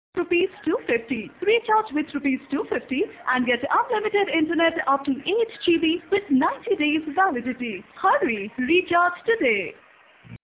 While we await more information on this new internet recharge coupon from Airtel, you can listen to the following recorded audio file for the announcement of this new coupon from the Airtel customer care.